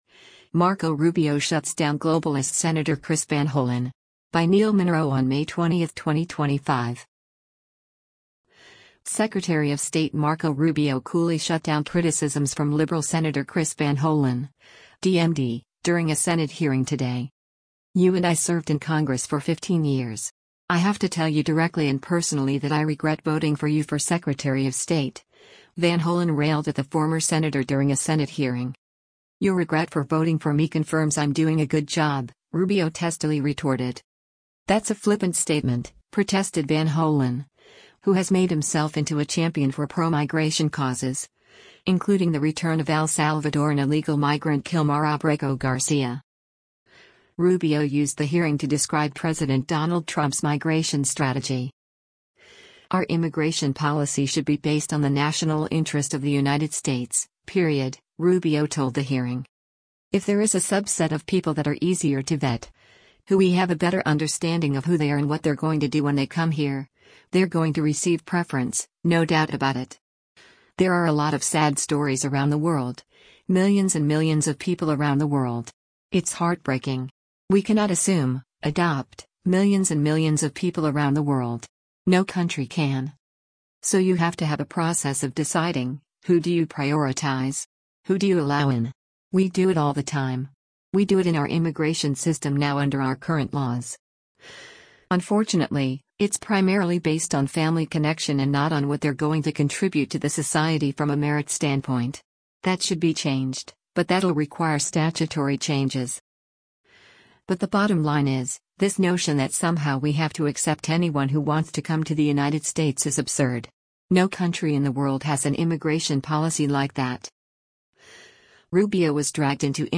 Secretary of State Marco Rubio coolly shut down criticisms from liberal Sen. Chris Van Hollen (D-MD) during a Senate hearing today.
“Your regret for voting for me confirms I’m doing a good job,”  Rubio testily retorted.
“He can’t make unsubstantiated statements ike that,” shouted Hollen, who had earlier complained that the El Salvador government had put a margherita drink on the table when he met with Abrego Garcia.